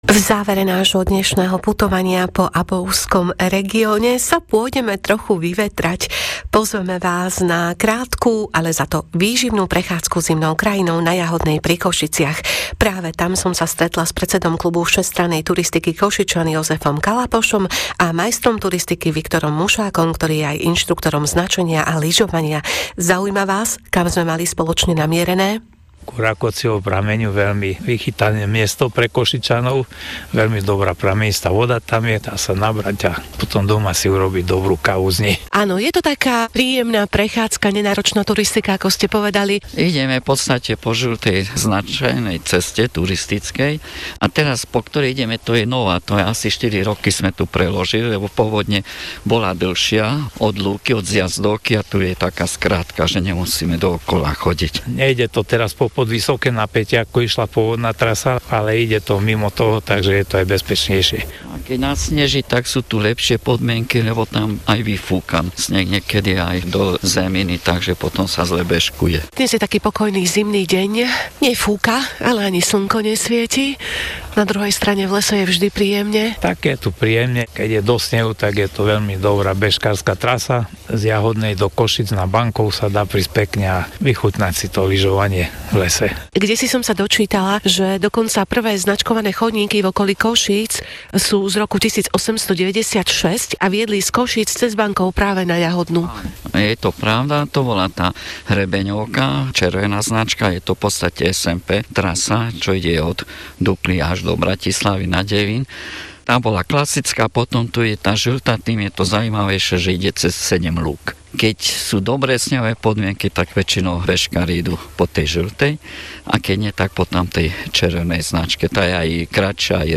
KST KOŠIČAN Hosť Rádia Regina - Východ - Jahodná Rozhovory s osobnosťami, ktoré stojí za to poznať.